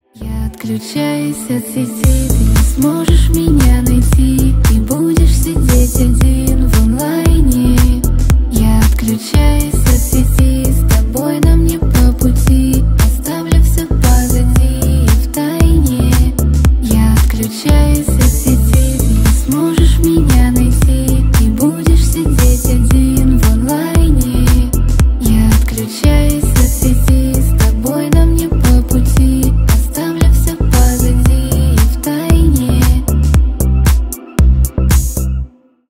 Поп Музыка
тихие
грустные